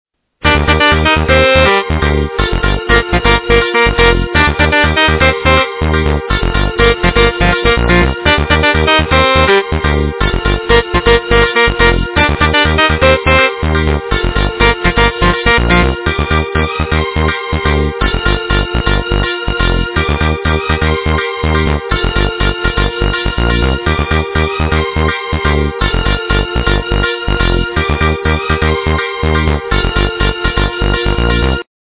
- западная эстрада
полифоническую мелодию